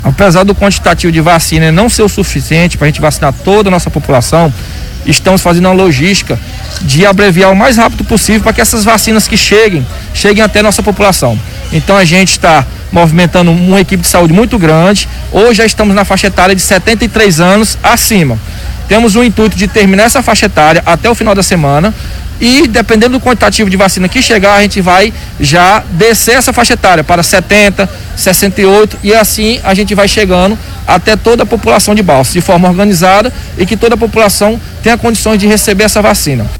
O Secretário de Saúde municipal, Raylson Félix, fala da movimentação de equipes para agilizar a campanha com maior rapidez.